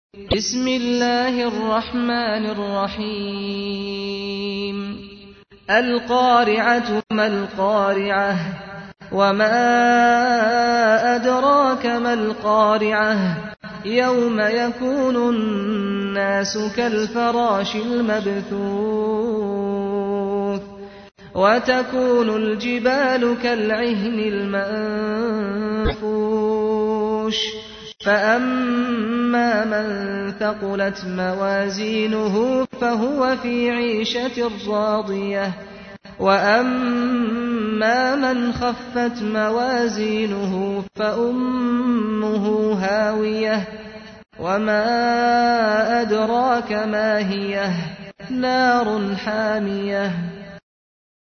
تحميل : 101. سورة القارعة / القارئ سعد الغامدي / القرآن الكريم / موقع يا حسين